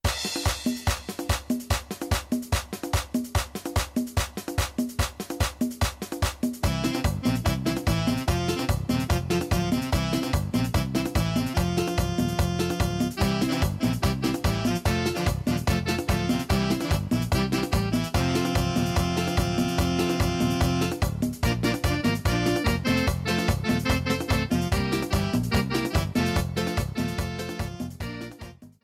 68-Pasito-Zapateado.mp3